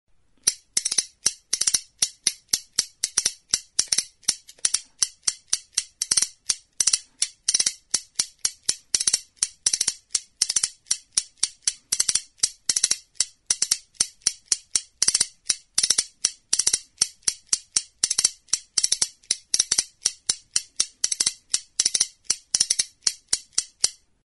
Music instrumentsKASKAMELAN
Idiophones -> Struck -> Indirectly
Recorded with this music instrument.
Intxaur azal erdiarekin egindako kaskamelana da. Hari berdearekin tinkaturik kanaberazko makiltxo bat dauka, intxaur azalaren kontra kolpatzeko.